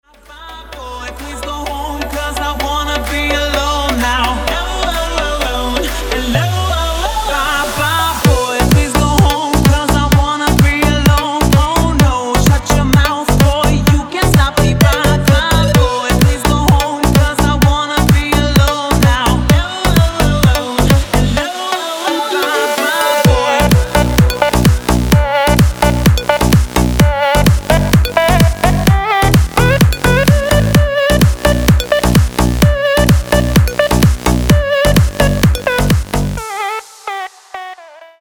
• Качество: 320, Stereo
восточные мотивы
зажигательные
Club House
энергичные
Зажигательная и энергичная музыка